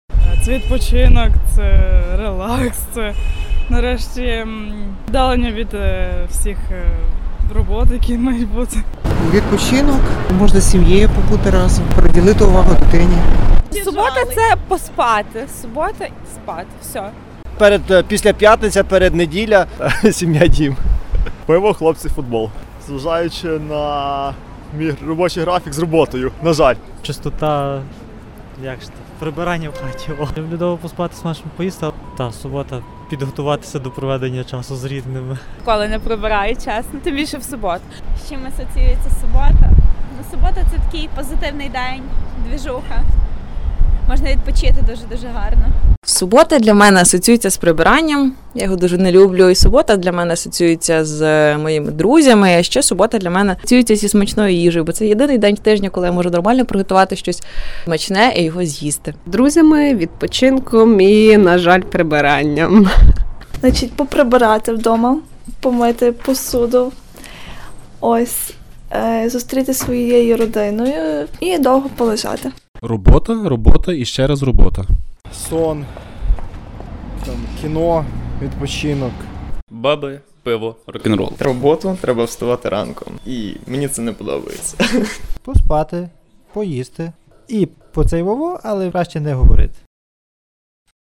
З чим у Вас асоціюється субота ? Кореспонденти West News провели опитування на вулицях Львова.
Львів’яни і гості міста